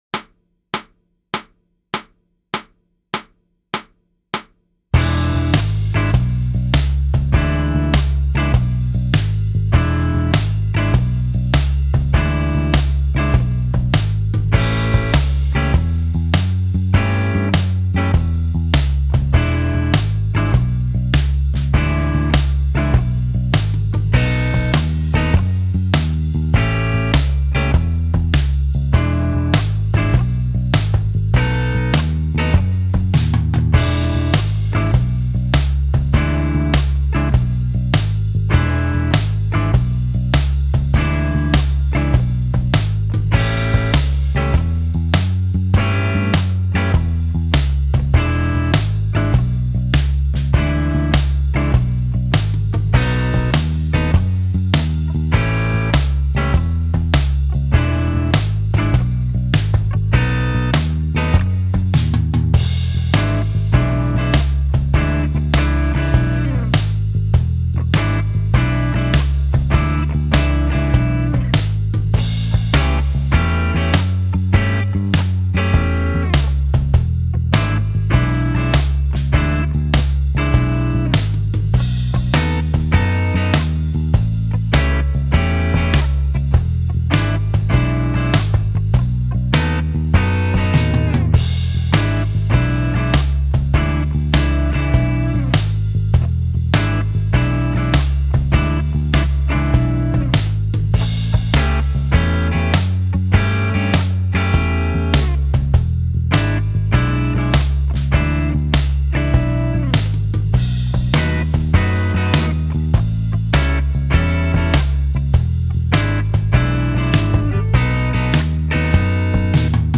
Jam Track - 70BPM